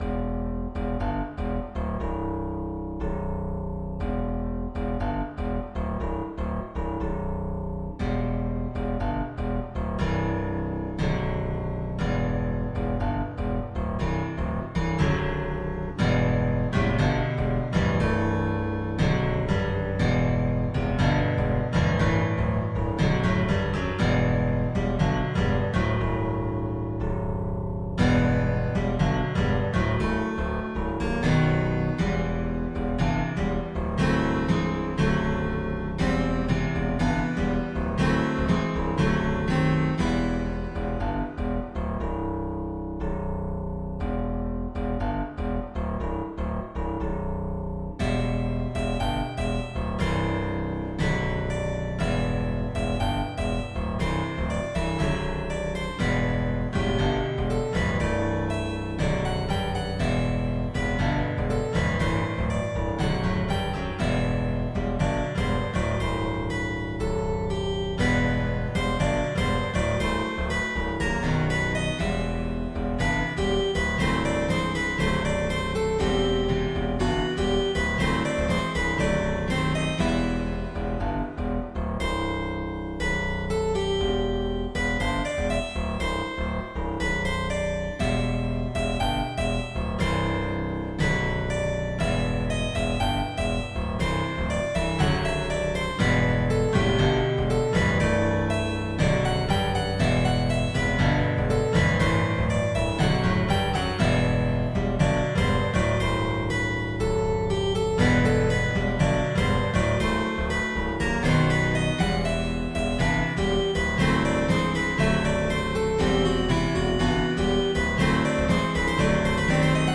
One (ish) on a chord progression built on repeating the famous bass line from Seven Nation Army, for steel-stringed guitars.
seven-gitarr-fix.mp3